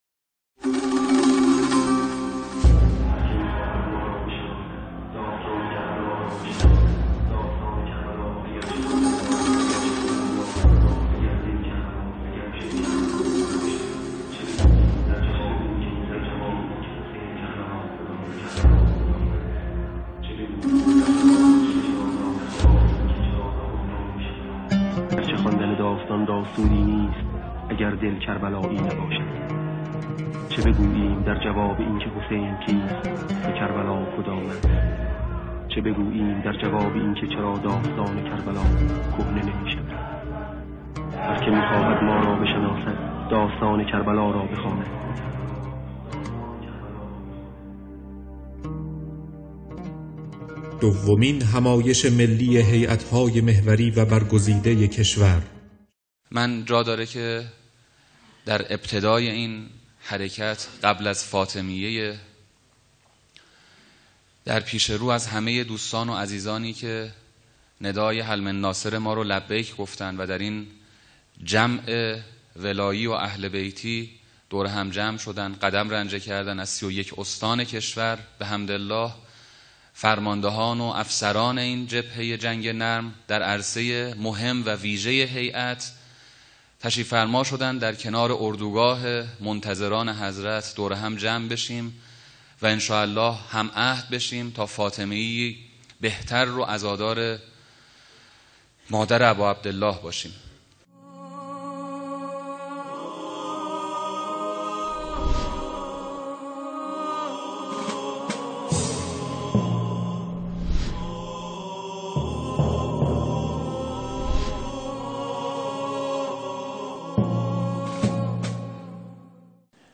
خلاصه دومین همایش ملی هیأت های محوری و برگزیده کشور | شهر مقدس قم - میعادگاه منتظران، مسجد مقدس جمکران - اسفندماه 1392 | جامعه ایمانی مشعر